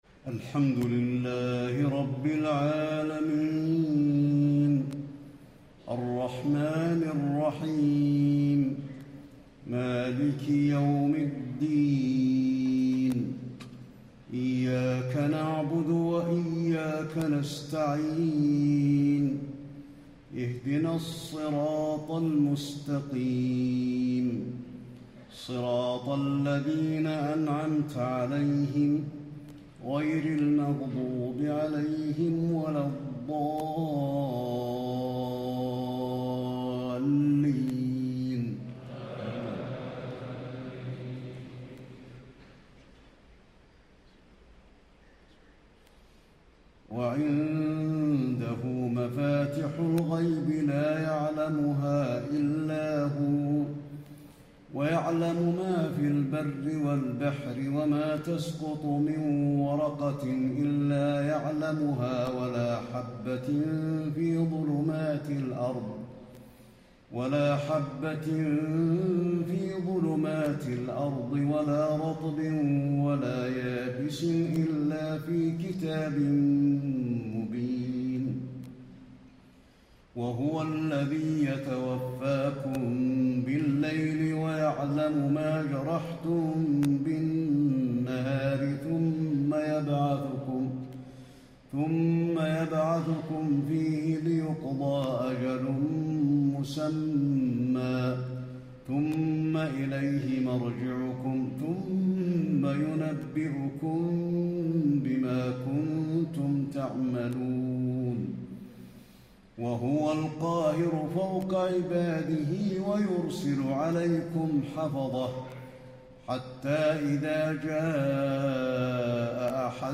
تهجد ليلة 27 رمضان 1435هـ من سورة الأنعام (59-110) Tahajjud 27 st night Ramadan 1435H from Surah Al-An’aam > تراويح الحرم النبوي عام 1435 🕌 > التراويح - تلاوات الحرمين